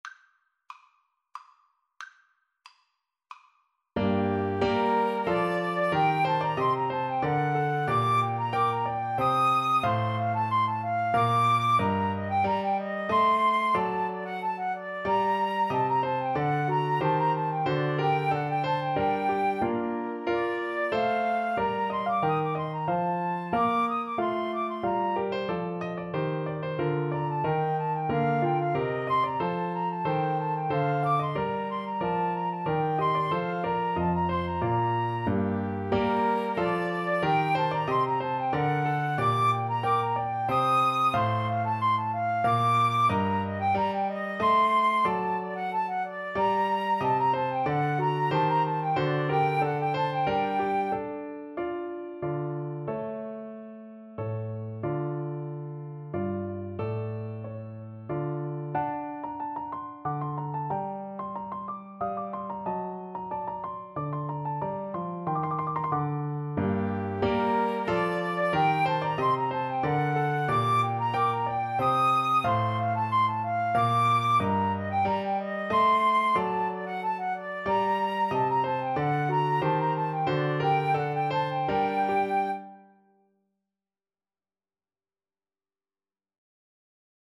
3/2 (View more 3/2 Music)
Allegro Moderato = c. 92 (View more music marked Allegro)
Classical (View more Classical Violin-Flute Duet Music)